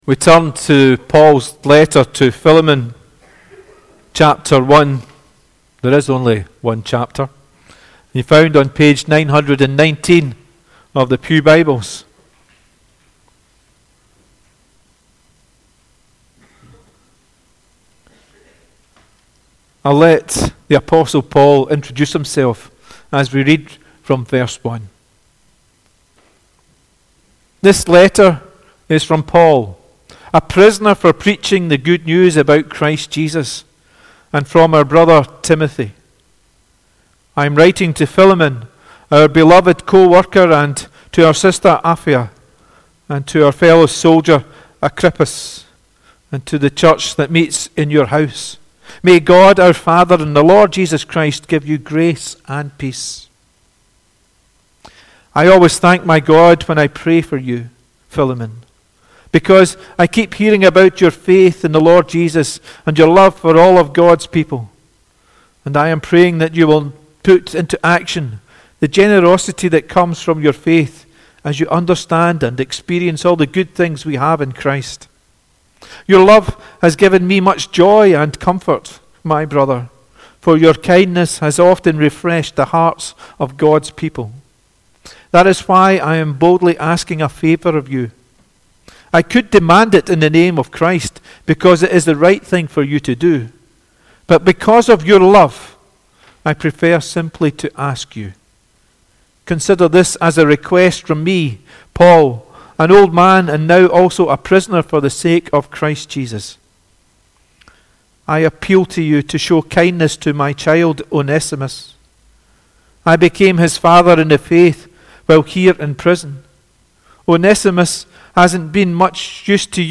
The Scripture Reading prior to the Sermon is Paul’s letter to Philemon